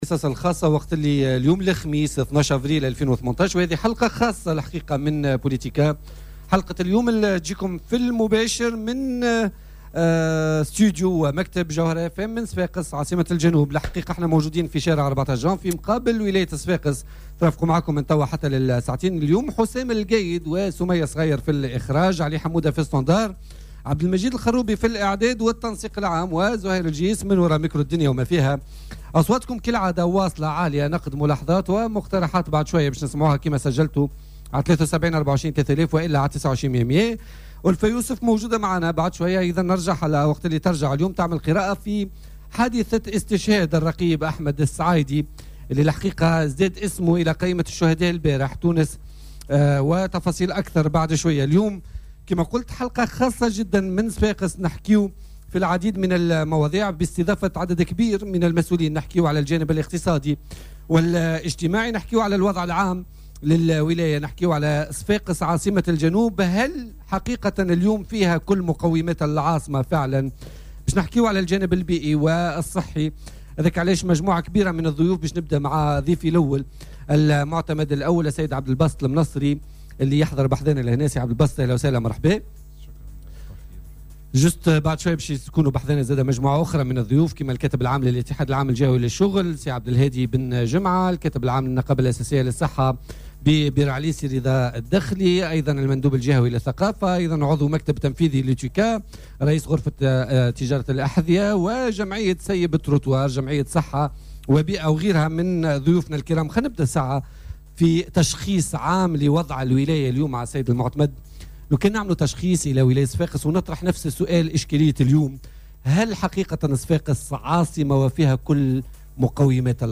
تبث "بوليتيكا" على "الجوهرة أف أم" اليوم من صفاقس في حلقة خاصة يؤثثها ضيوف يمثلون مؤسسات الدولة بالجهة بالاضافة إلى المجتمع المدني والمنظمات الوطنية.